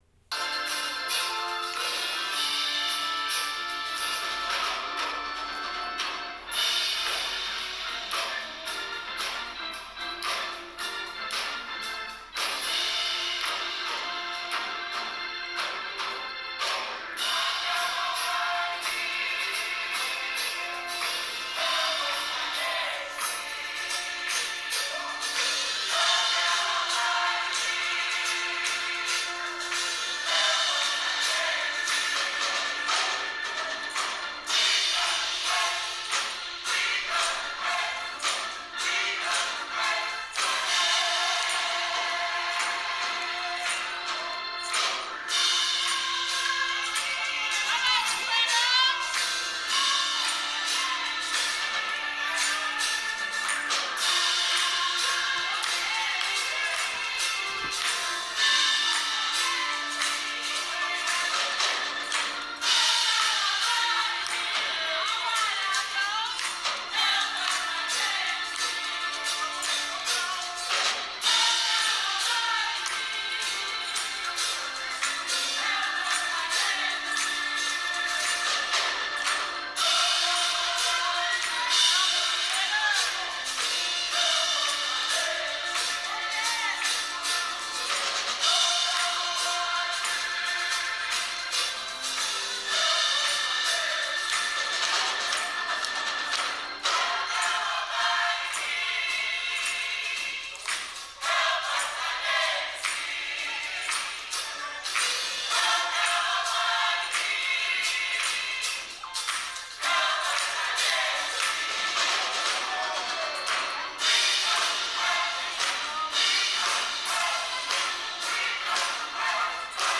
F F 4/4 118
Choir Gospel
Tenor Alto Soprano